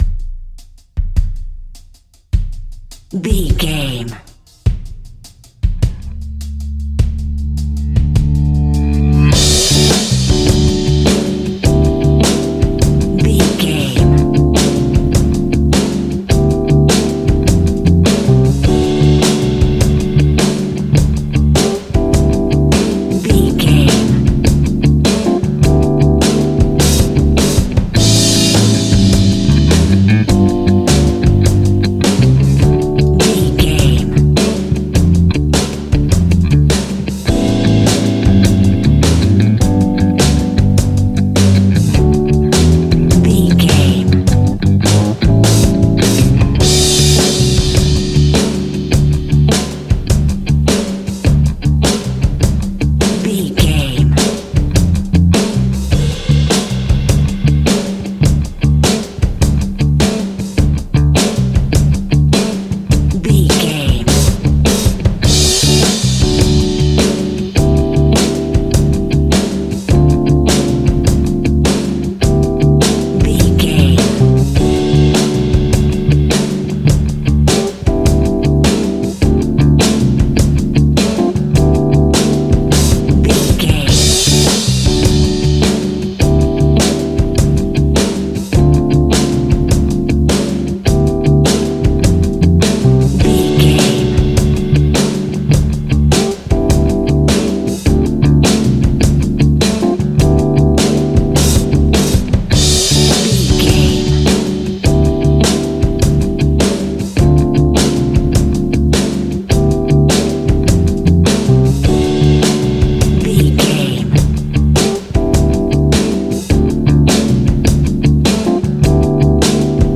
Ionian/Major
D
funky
uplifting
bass guitar
electric guitar
organ
percussion
drums
saxophone
groovy